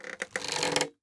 Minecraft Version Minecraft Version snapshot Latest Release | Latest Snapshot snapshot / assets / minecraft / sounds / item / crossbow / loading_middle4.ogg Compare With Compare With Latest Release | Latest Snapshot